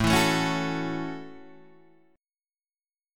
A6 chord {5 7 7 6 7 5} chord